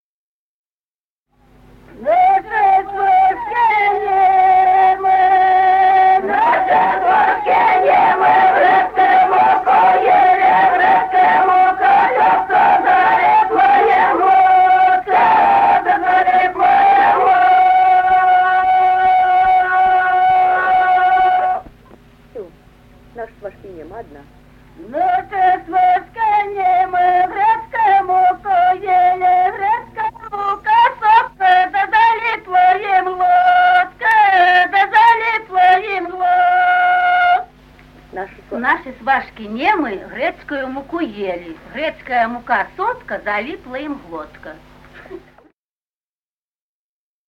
Народные песни Стародубского района «Наши свашки немы», свадебная, дру́жки поют свашкам.
c. Остроглядово.